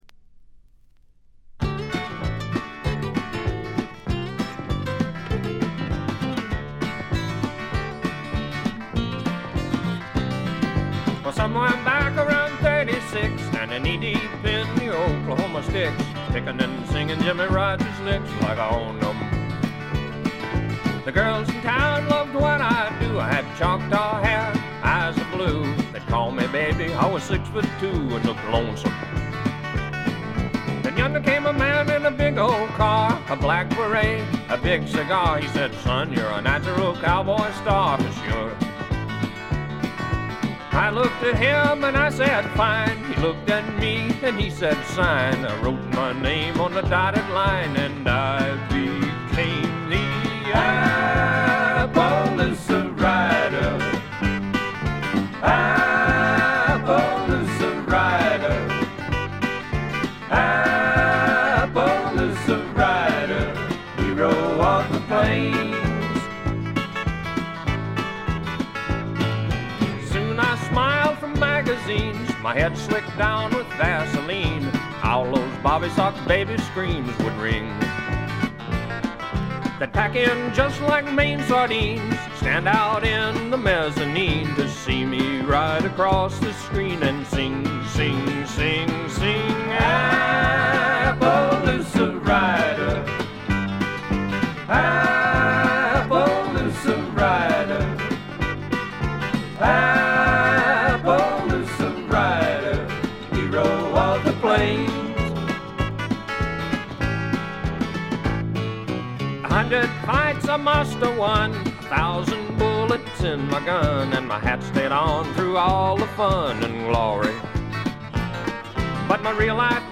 部分試聴ですが、ところどころでチリプチ、散発的なプツ音少し。
いかにもテキサス／ダラス録音らしいカントリー系のシンガー・ソングライター作品快作です。
ヴォーカルはコクがあって味わい深いもので、ハマる人も多いと思いますね。
試聴曲は現品からの取り込み音源です。
Recorded At - January Sound Studio